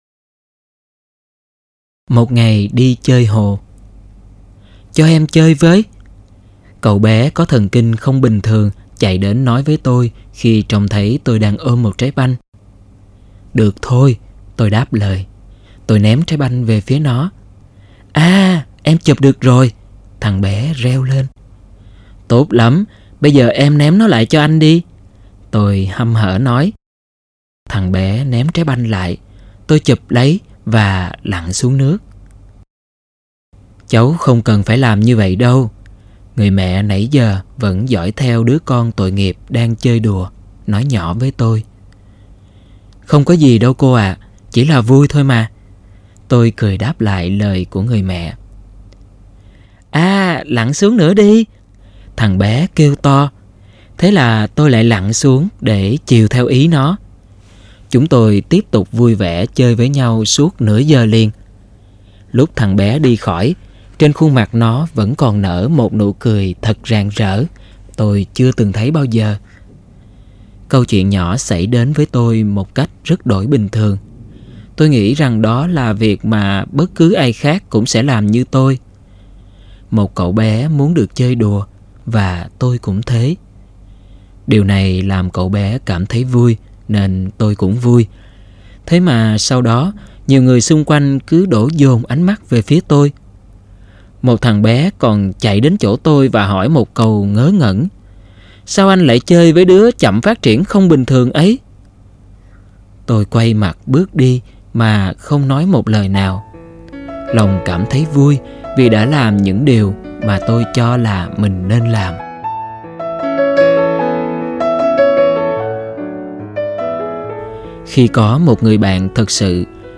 Sách nói Chicken Soup 4 - Chia Sẻ Tâm Hồn Và Quà Tặng Cuộc Sống - Jack Canfield - Sách Nói Online Hay